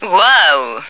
flak_m/sounds/female2/est/F2ohyeah.ogg at 098bc1613e970468fc792e3520a46848f7adde96